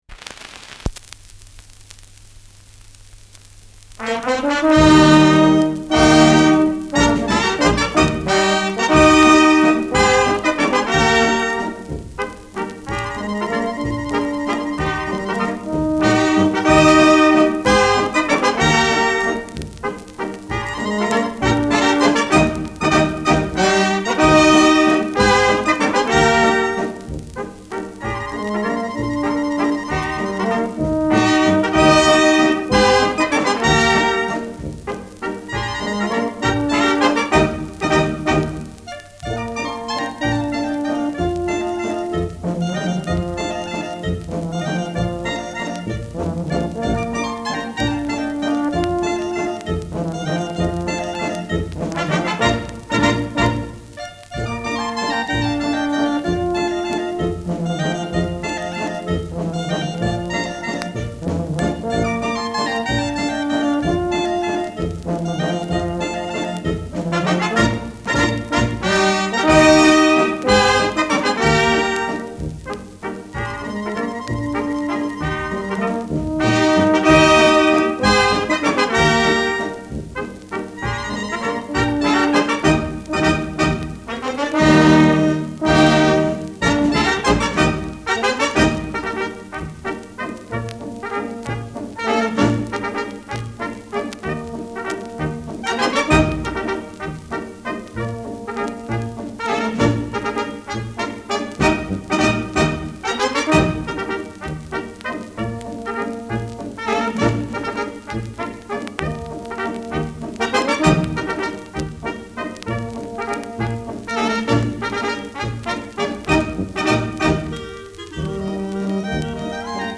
Schellackplatte
Vereinzelt leichtes Knacken
[Zürich] (Aufnahmeort)
Folkloristisches Ensemble* FVS-00015